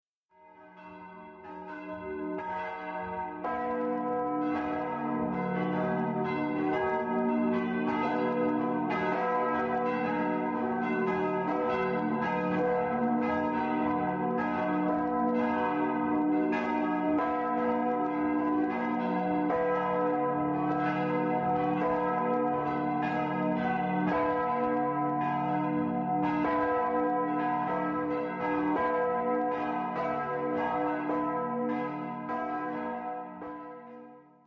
Das Glockengeläut von 1816 besteht aus vier Glocken, gegossen 1816 von Friedrich Gruhl in Kleinwelka.
Die-Glocken-der-Christuskirche-Bischofswerda.mp3